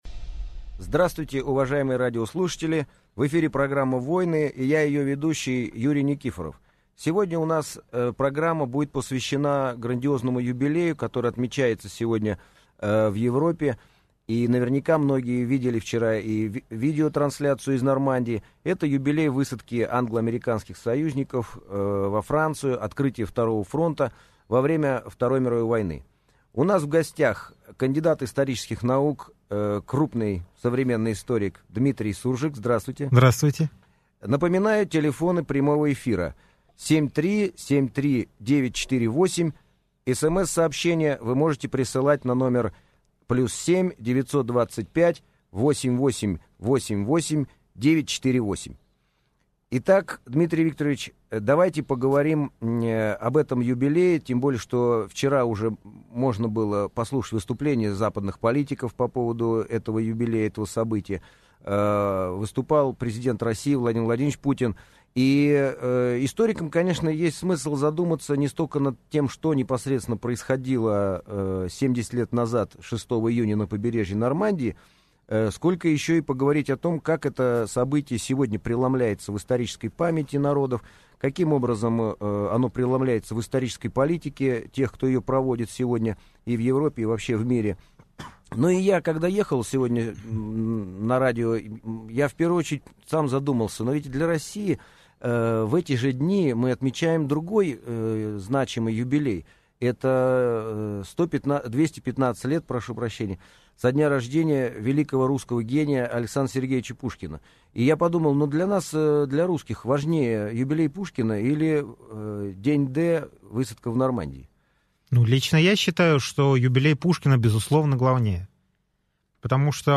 Аудиокнига Высадка союзников в Нормандии.